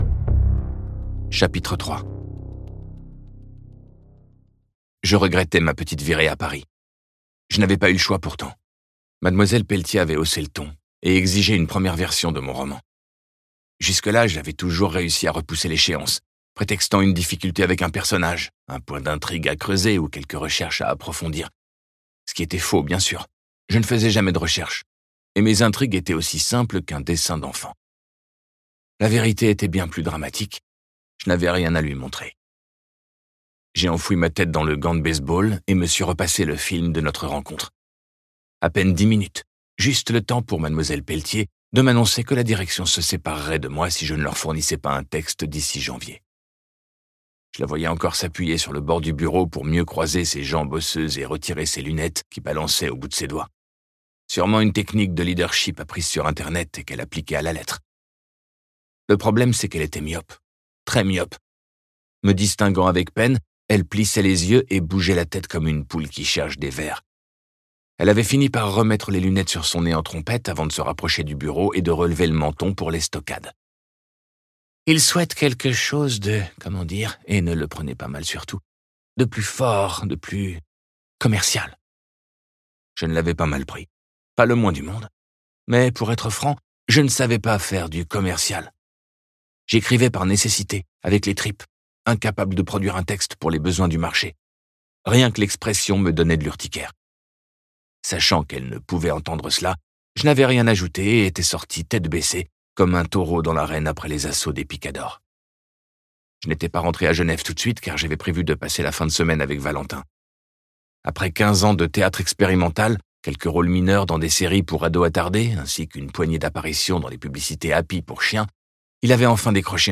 Ce livre audio est interprété par une voix humaine, dans le respect des engagements d'Hardigan.